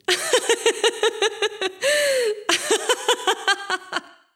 Mujer riendo 1
carcajada
mujer
risa
Sonidos: Acciones humanas
Sonidos: Voz humana